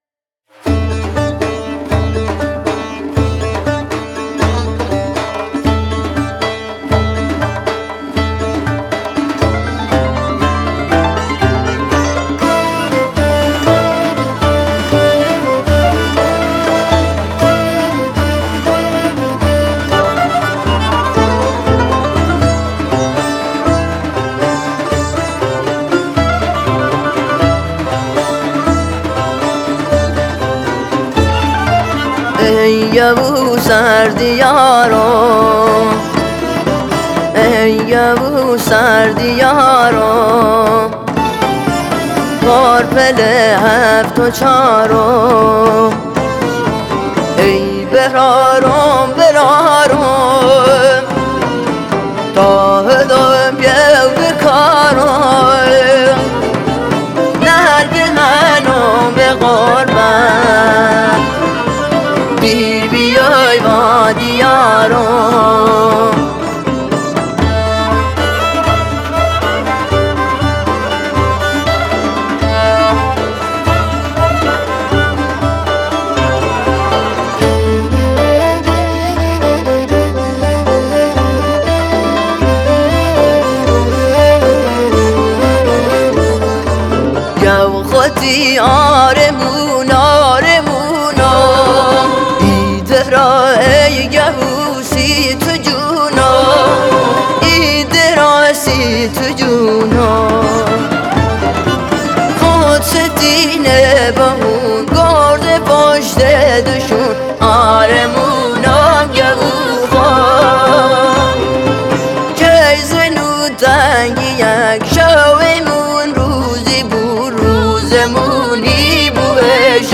یه آهنگ لری